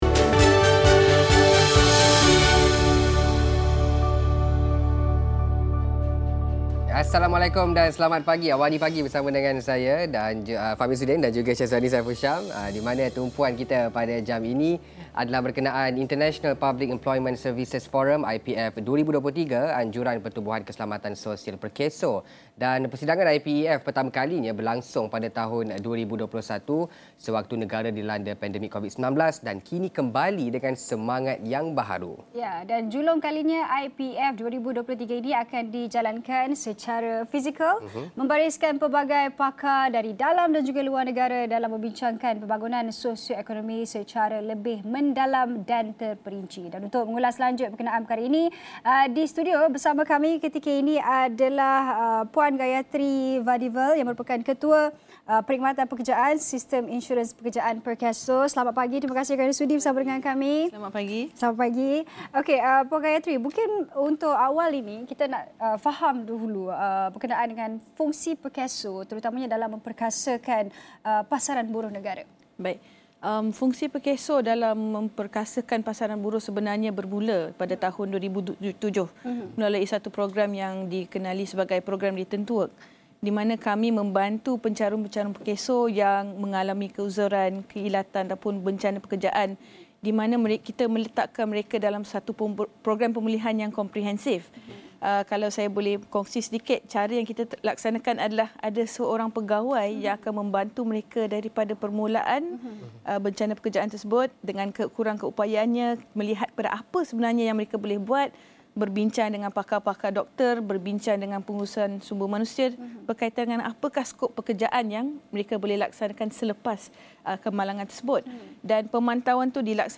Diskusi